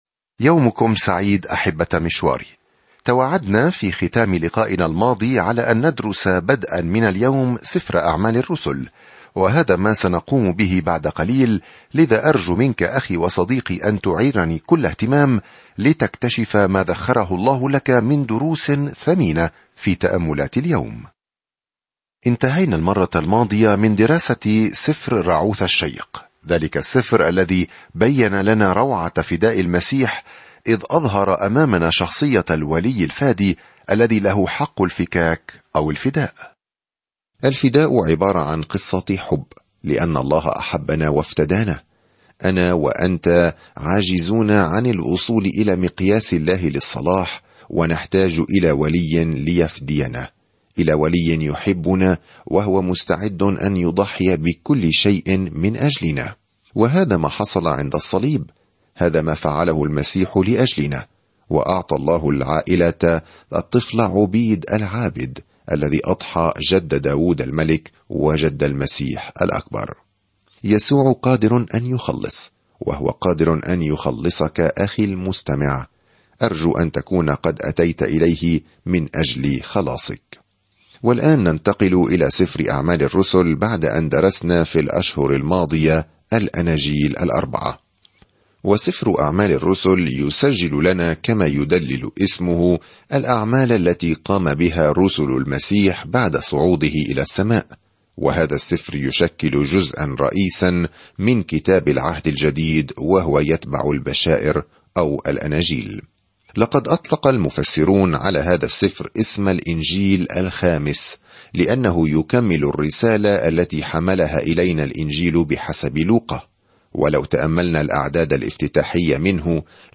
الكلمة أَعْمَالُ ٱلرُّسُلِ 1:1-3 ابدأ هذه الخطة يوم 2 عن هذه الخطة يبدأ عمل يسوع في الأناجيل ويستمر الآن من خلال روحه، حيث تُزرع الكنيسة وتنمو في جميع أنحاء العالم. سافر يوميًا عبر سفر أعمال الرسل وأنت تستمع إلى الدراسة الصوتية وتقرأ آيات مختارة من كلمة الله.